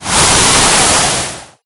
Wind8.ogg